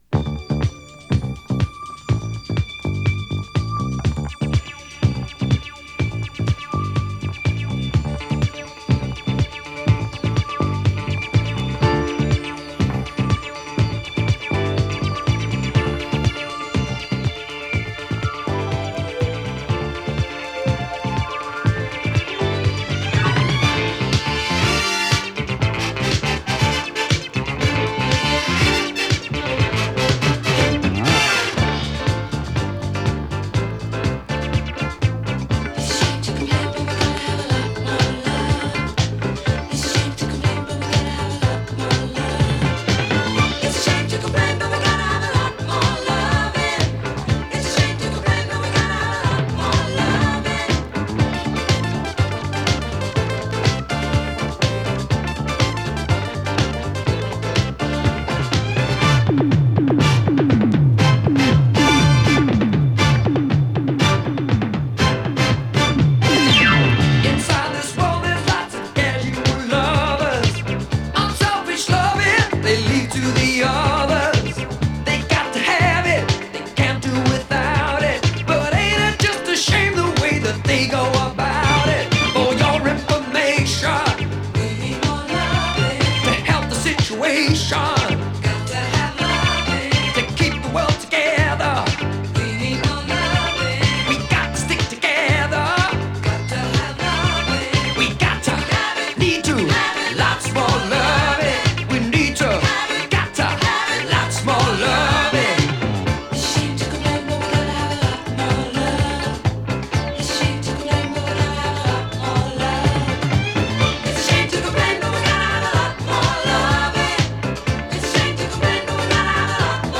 キッチュ シンセ 熱 ホーン ガラージュ